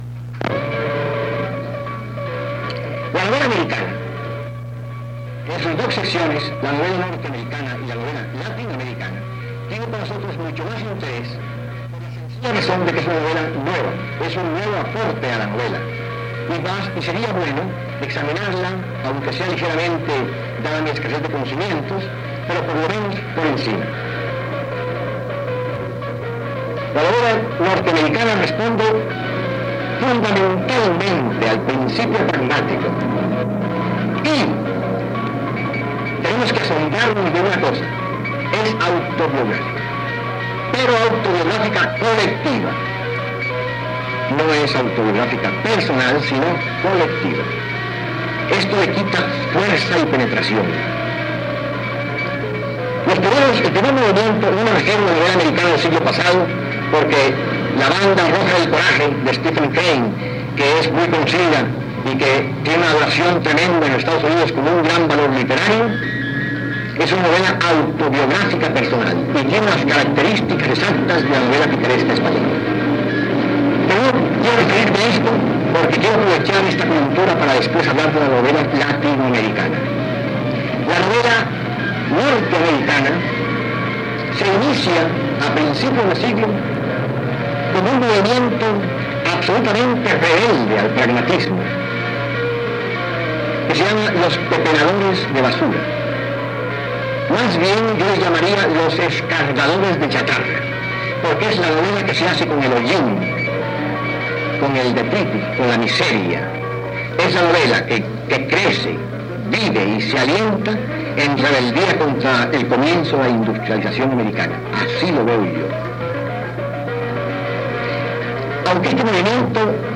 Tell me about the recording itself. ANALÓGICO - Analógicos: 1 - Analógicos: 1 Velocidad: 33.5 revoluciones por minuto